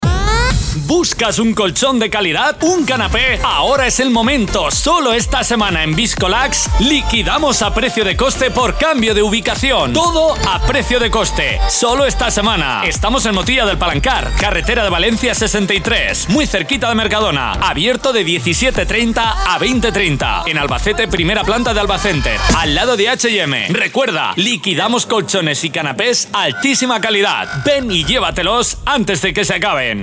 NUESTRAS VOCES
HOMBRE 1
HOMBRE-1.mp3